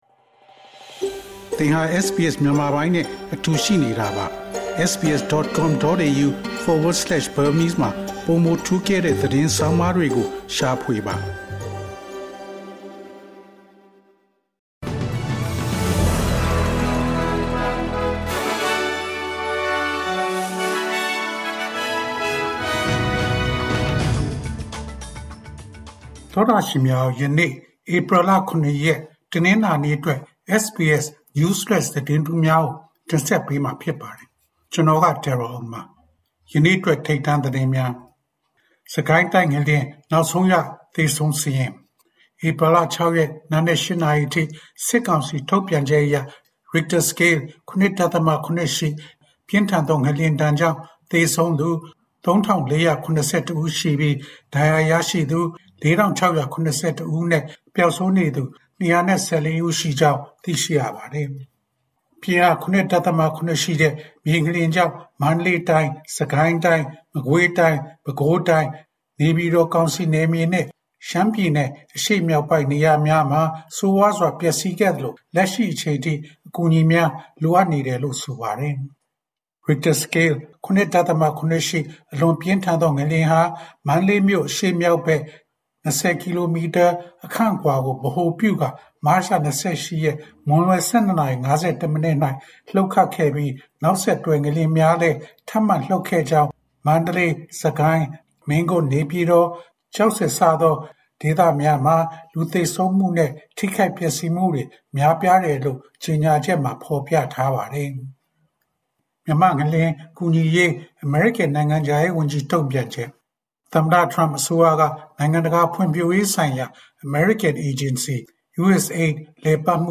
SBS မြန်မာ ၂၀၂၅ ခုနှစ် ဧပြီ ၇ ရက် နေ့အတွက် News Flash သတင်းများ။